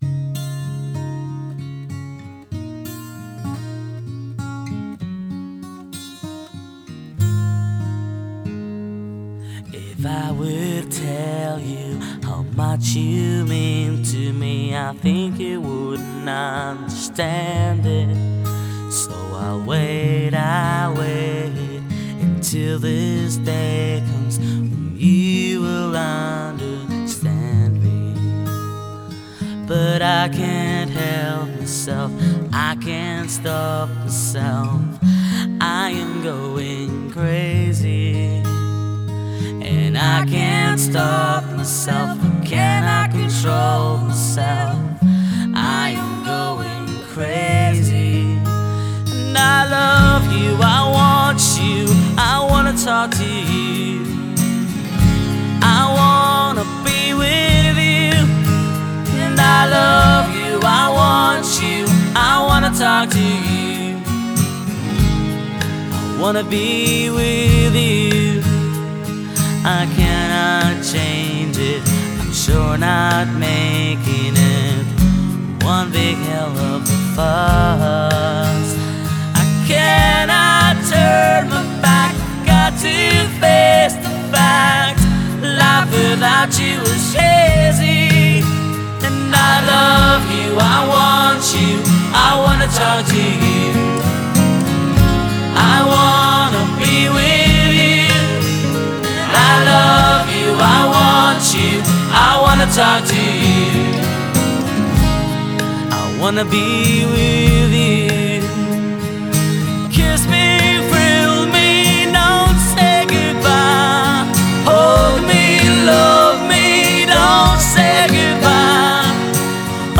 европейская фолк/рок группа